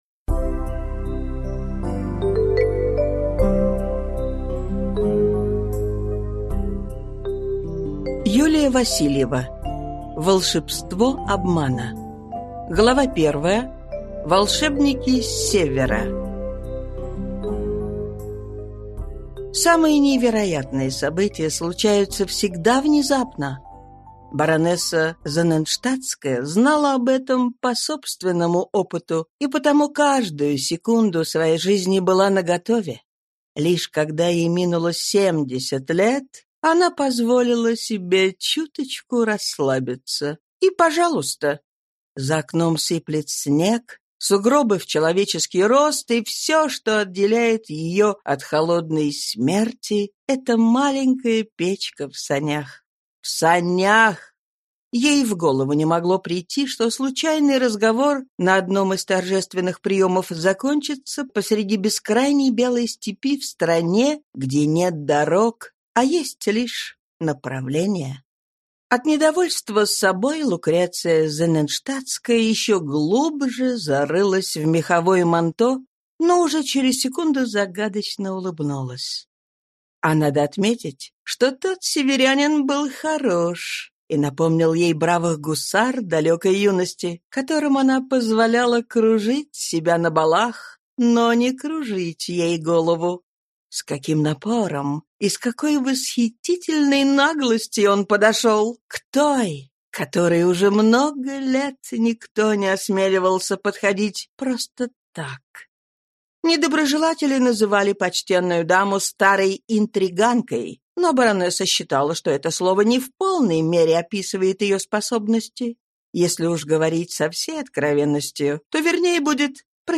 Аудиокнига Волшебство обмана | Библиотека аудиокниг